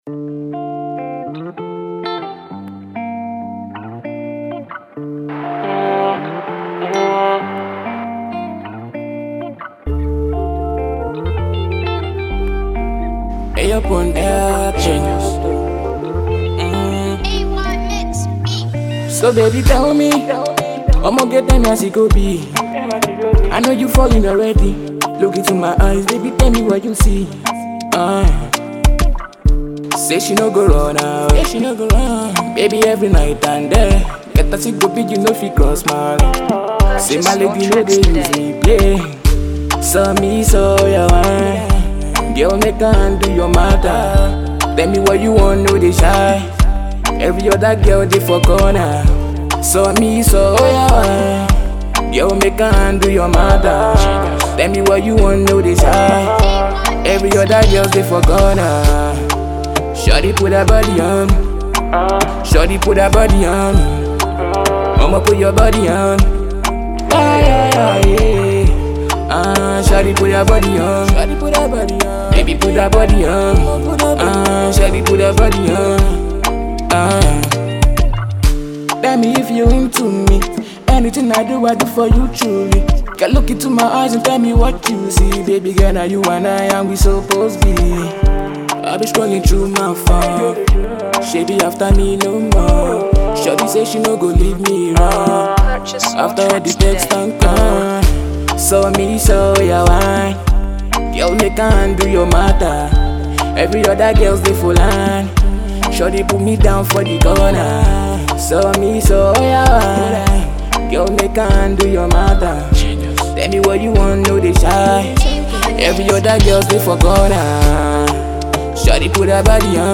Fast rising afro singer